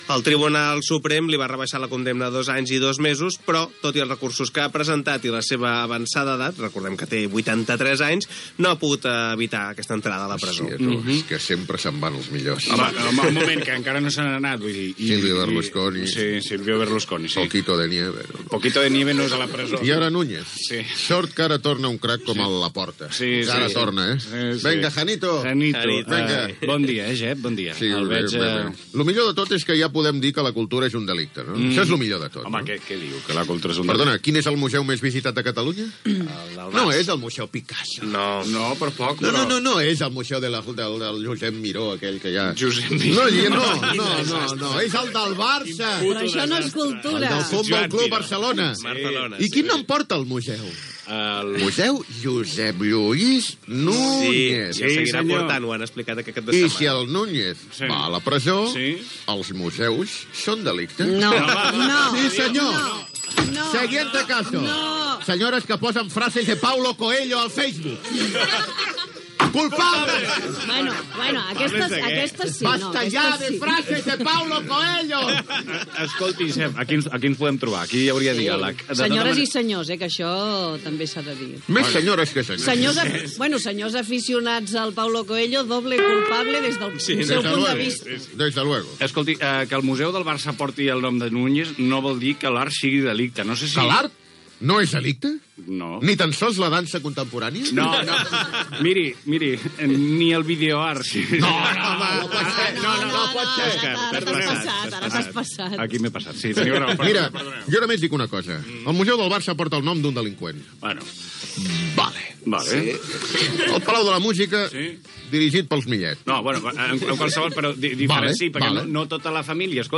Comentaris humorístics sobre l'entrada a la presó del constructor Josep Lluís Núñez, que fou també pesident del F.C. Barcelona
Entreteniment